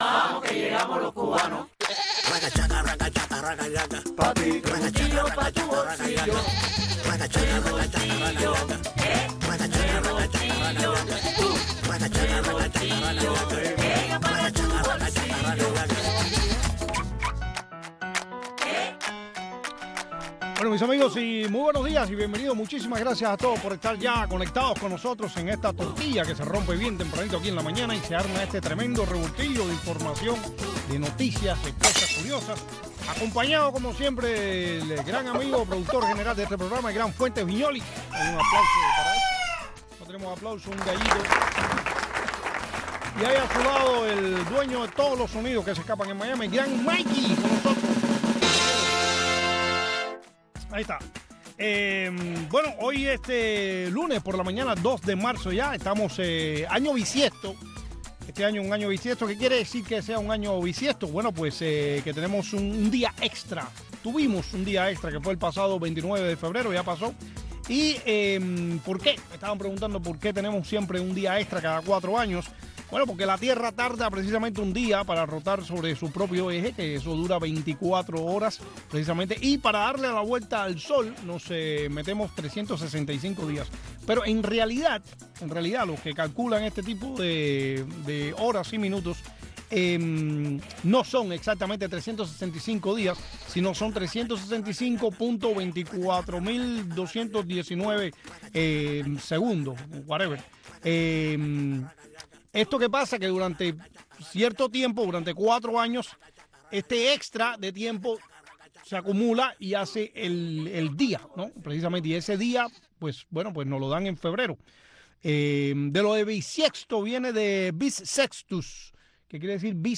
comparte entrevistas, anécdotas y simpáticas ocurrencias